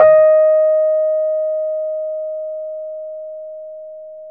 RHODES-EB4.wav